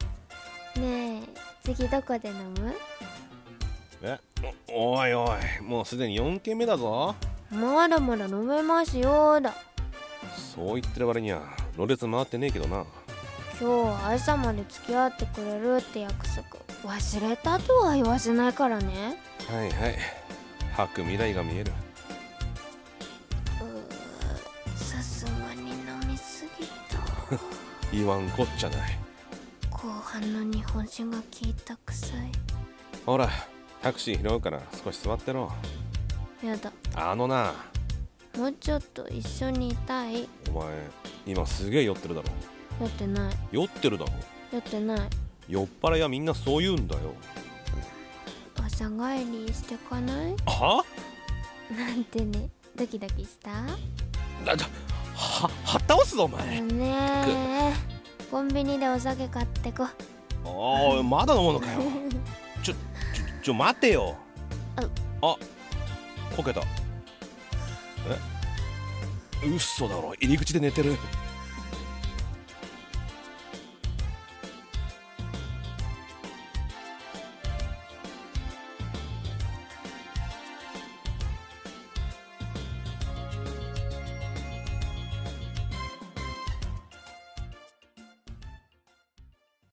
【声劇台本】朝まで一緒に